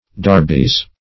Search Result for " darbies" : The Collaborative International Dictionary of English v.0.48: Darbies \Dar"bies\ (d[aum]r"b[i^]z), n. pl.